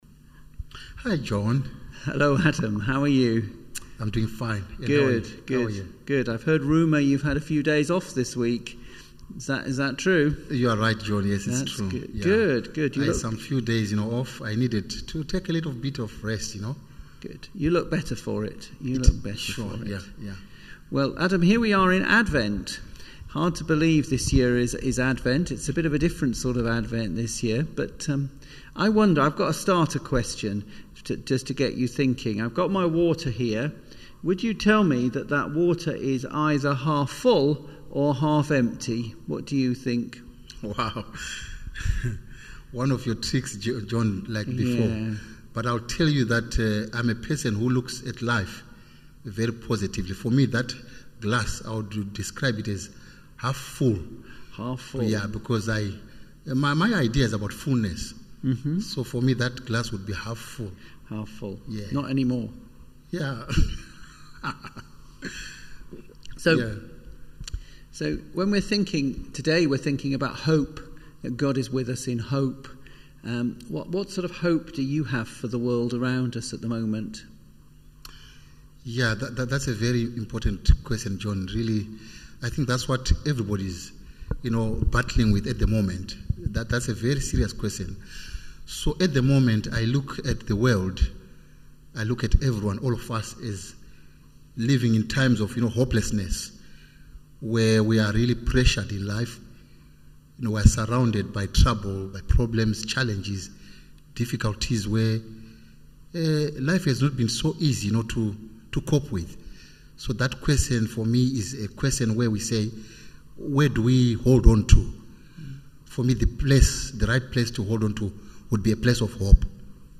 latestsermon-4.mp3